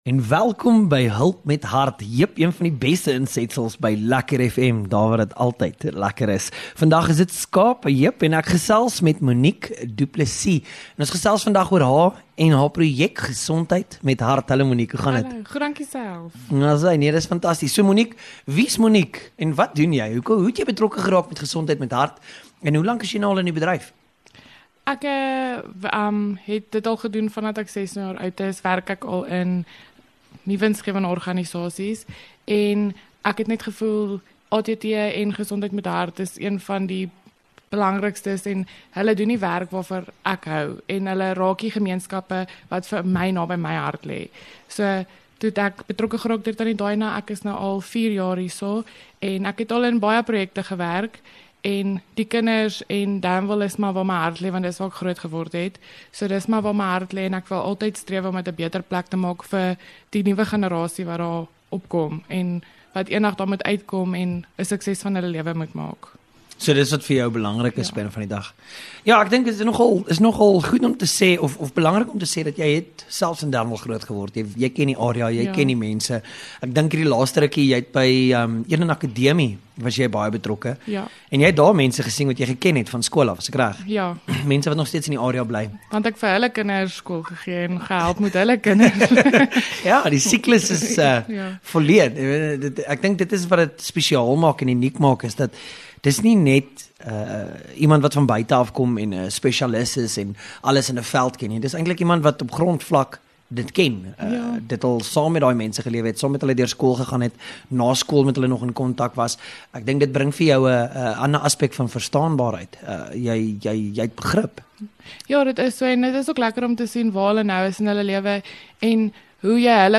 LEKKER FM | Onderhoude 16 Oct Hulp met Hart